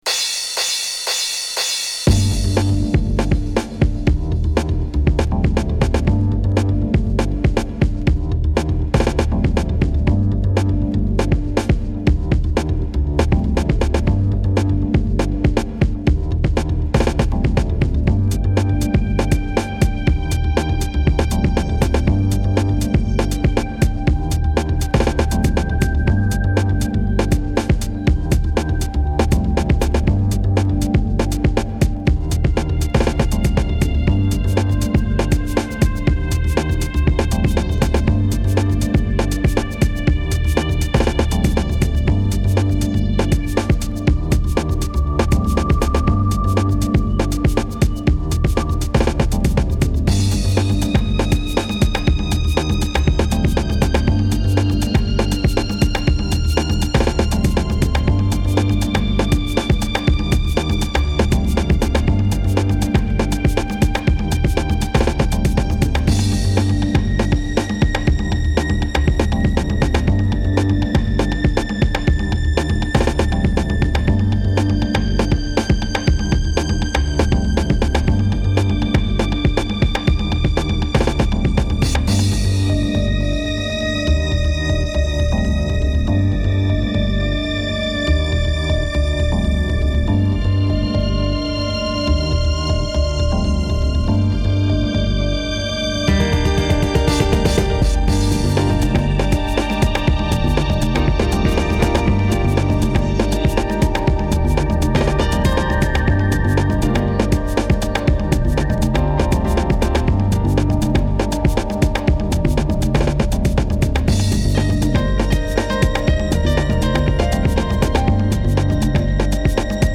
House / Techno
ミニマルで耽美的な世界に誘うミラクルトラック。